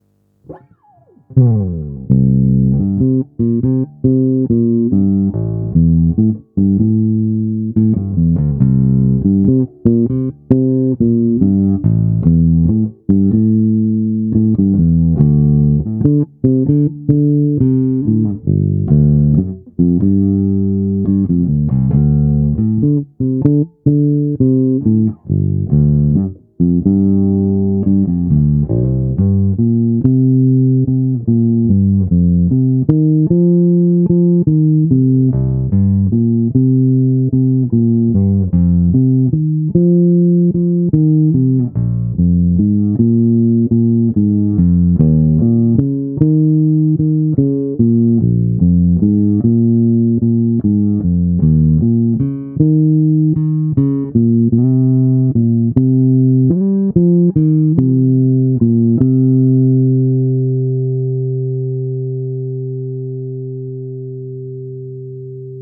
Nahrávky po úpravě se strunama Olympia:
Olympia nová tonovka stažená